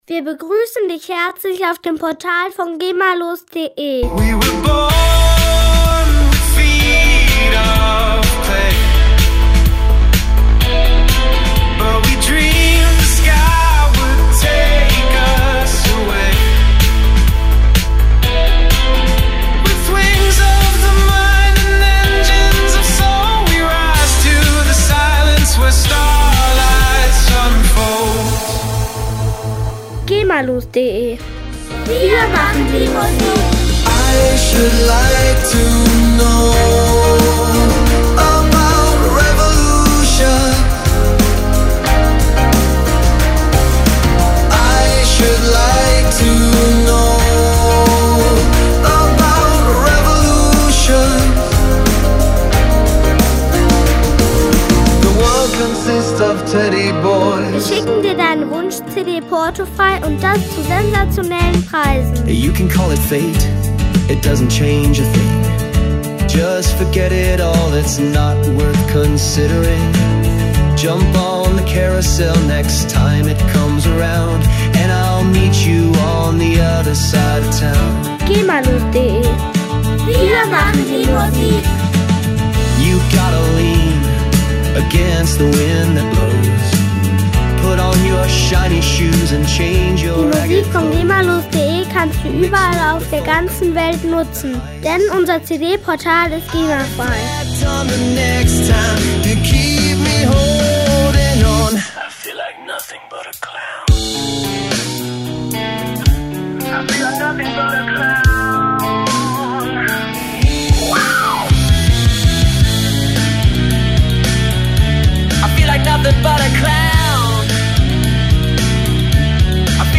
Musikstil: Alternative Rock